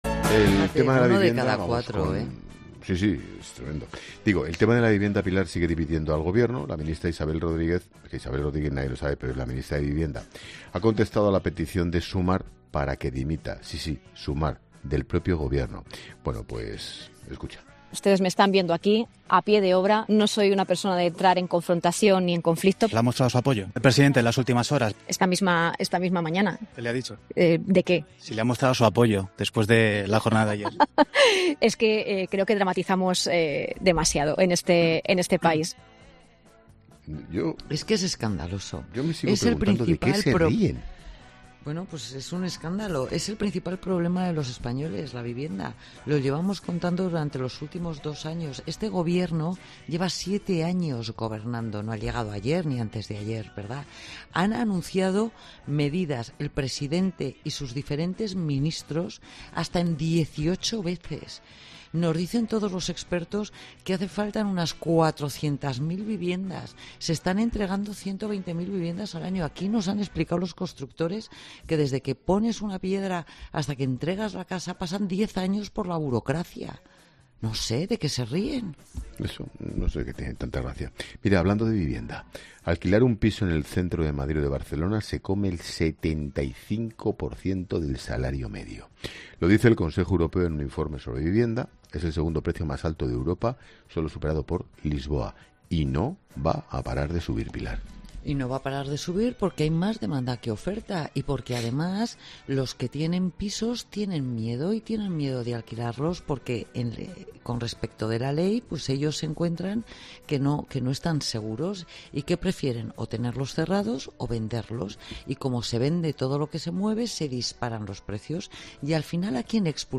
Ángel Expósito analiza con la experta económica y directora de Mediodía COPE, Pilar García de la Granja, la crisis de la vivienda en España y división en el gobierno